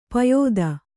♪ payōda